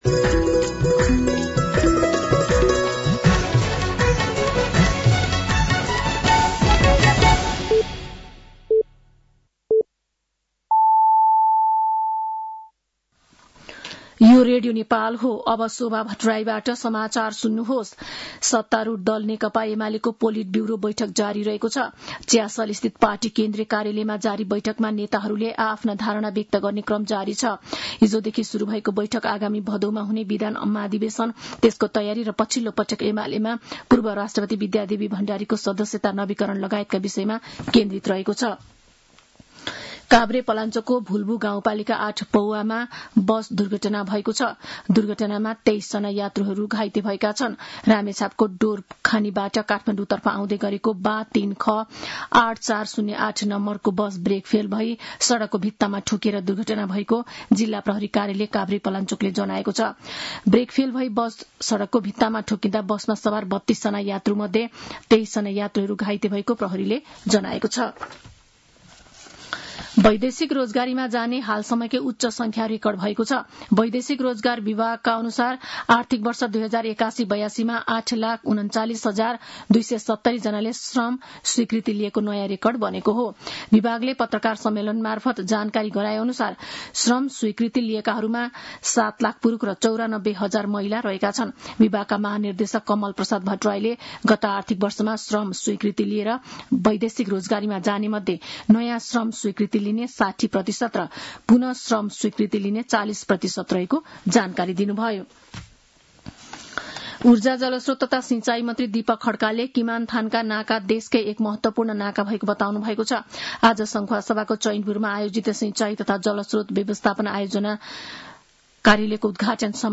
साँझ ५ बजेको नेपाली समाचार : ३ साउन , २०८२